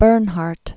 (bûrnhärt, bĕr-när), Sarah Originally Henriette Rosine Bernard. 1844-1923.